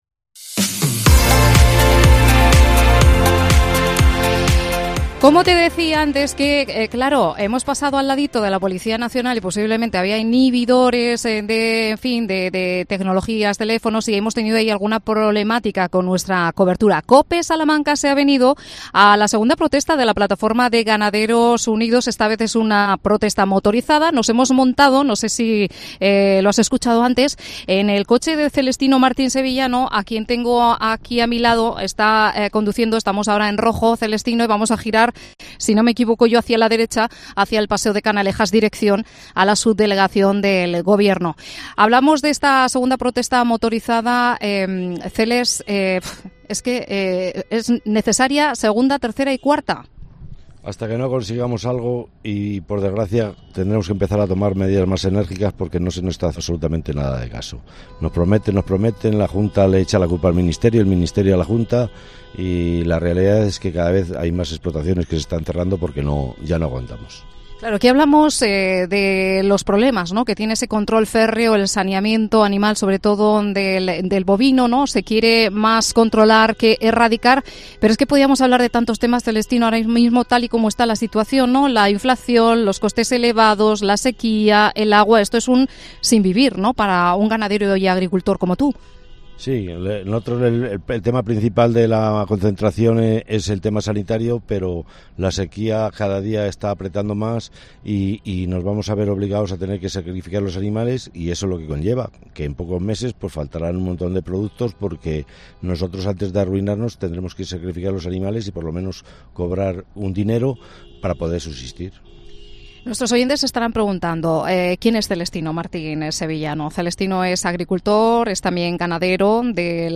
AUDIO: COPE SALAMANCA se monta en la protesta motorizada de la Plataforma de Ganaderos Unidos.